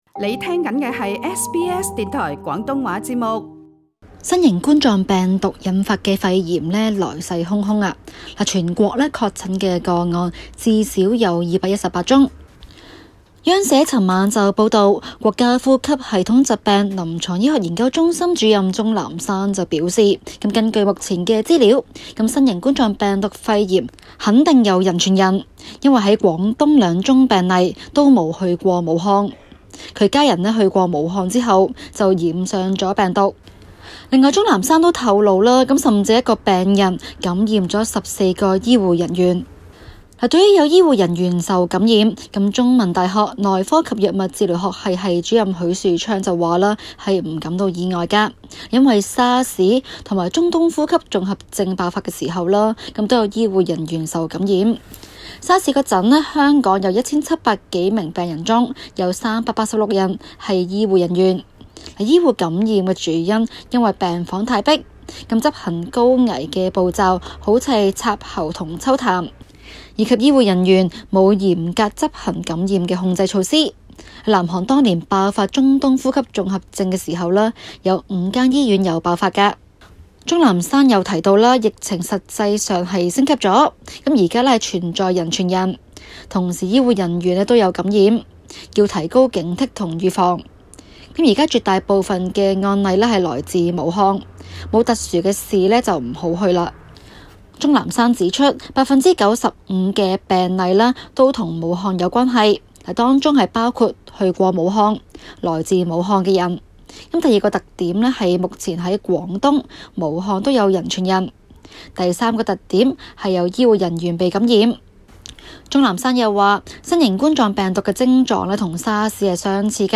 中港快讯